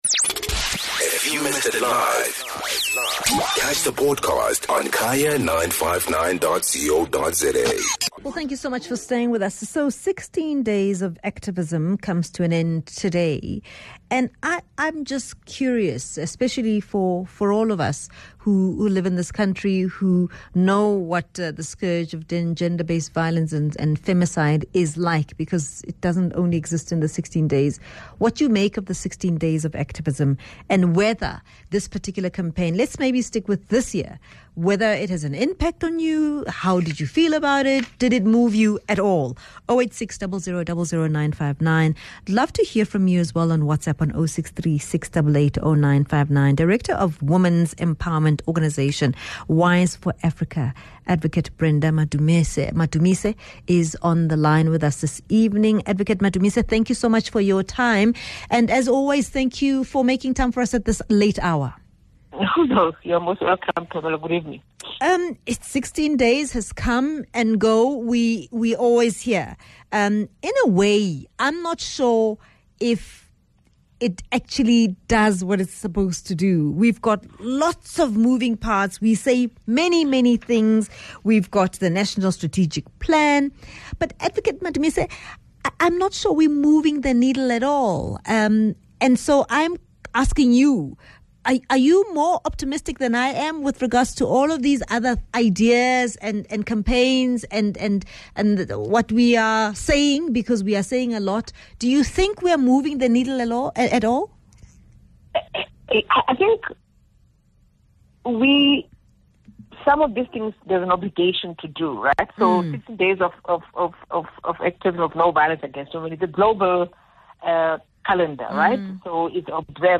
10 Dec Discussion: 16 Days of Activism